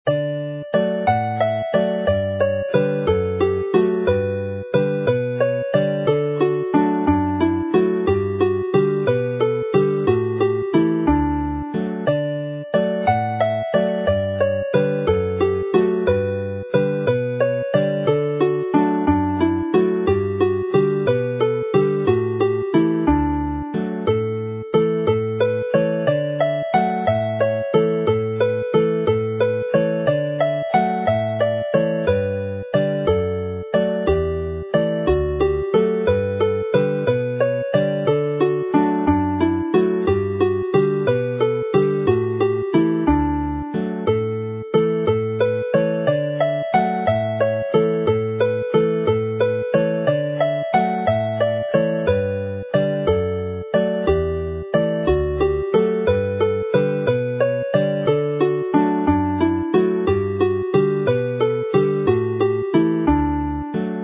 Play slowly